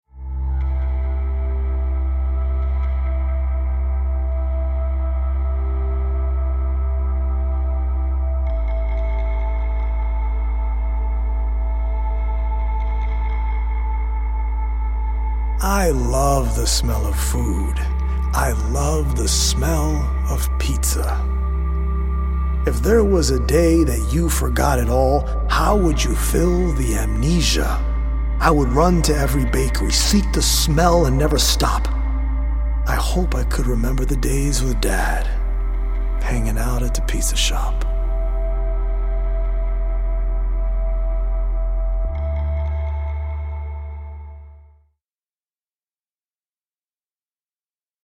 original poems
healing Solfeggio frequency music
EDM producer